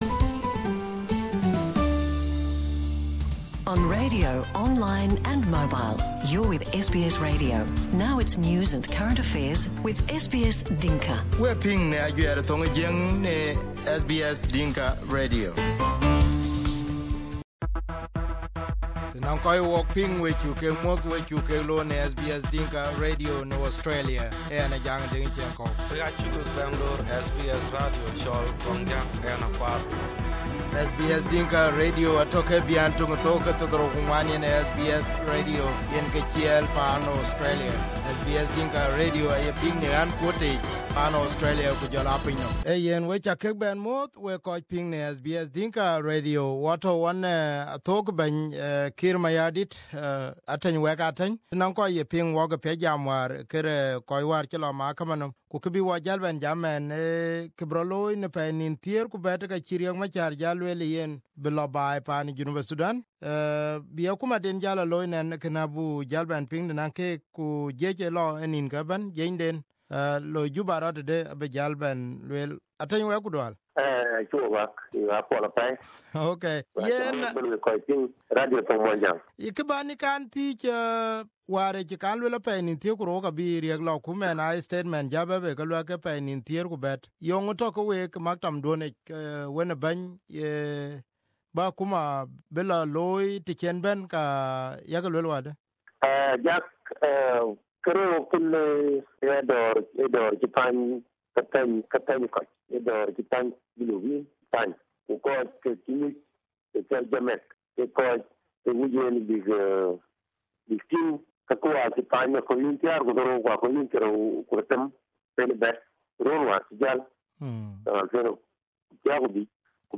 In an interview with South Sudanese Presidential spokesperson Ateny Wek Ateny, we asked him if he could explain why Bokosoro has not appeared in court. Ateny said that the arrest of the former Governor is a matter of national security but failed to disclose charges. Here is the interview